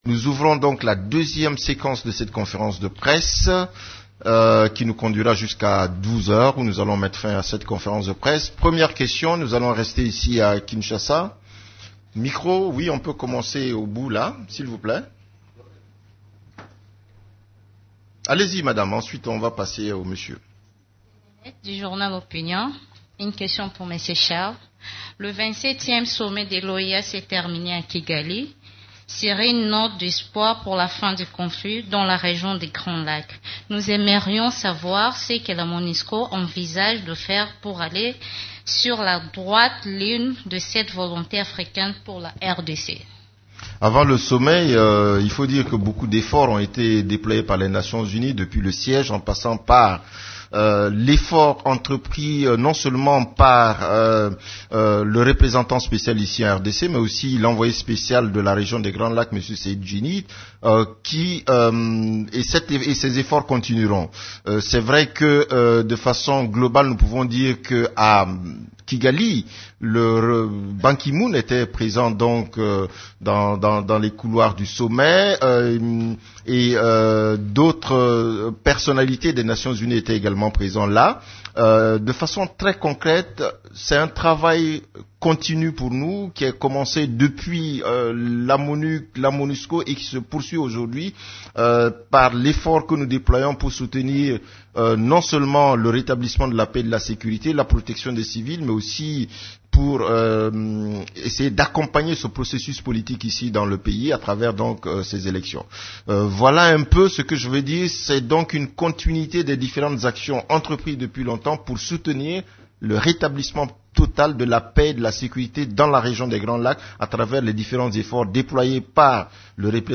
Conférence de presse du 20 juillet 2016
La conférence de presse hebdomadaire des Nations unies du mercredi 20 juillet à Kinshasa a porté sur les activités des composantes de la MONUSCO, les activités de l’équipe-pays et la situation militaire.